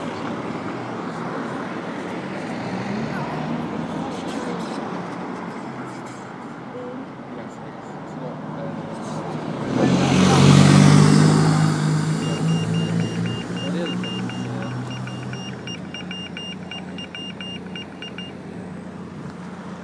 Techno pedestrian crossing in Bingley